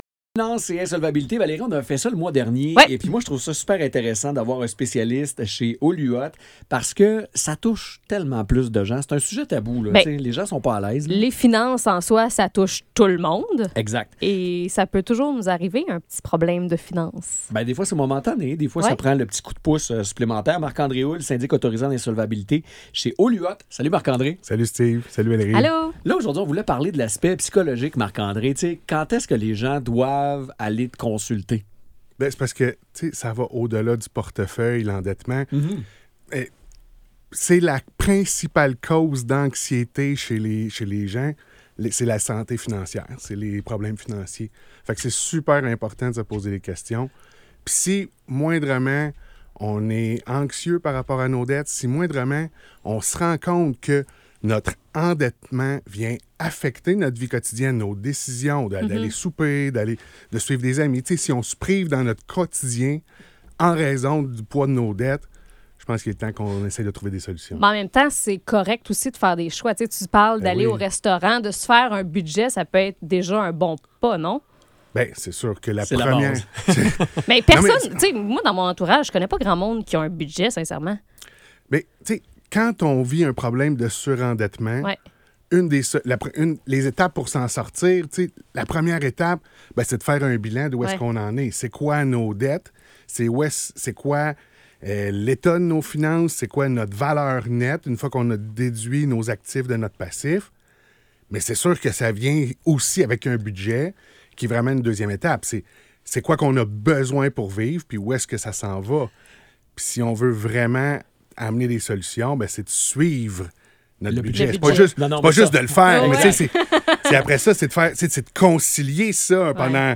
Chronique Radio 107.7 FM :